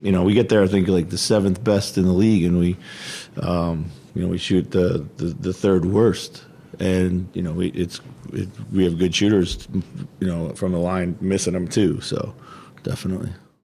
Coach Chris Finch on the 15 missed free throws.